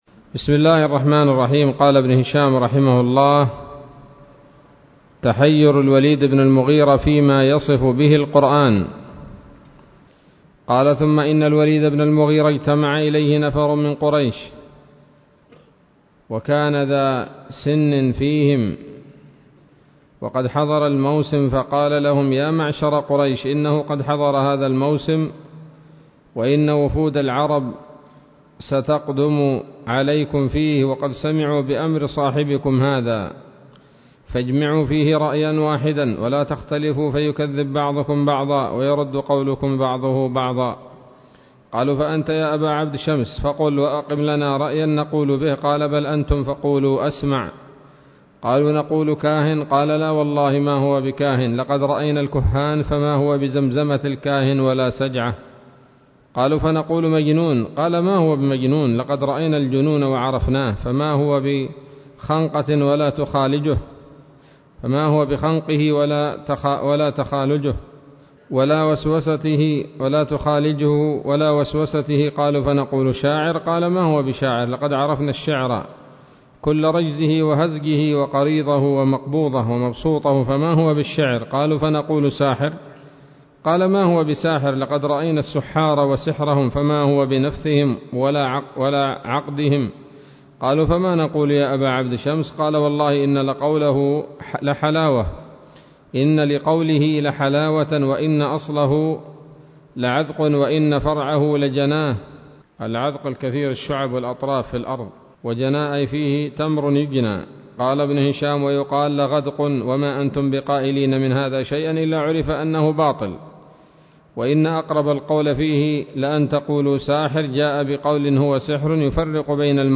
الدرس الثامن والعشرون من التعليق على كتاب السيرة النبوية لابن هشام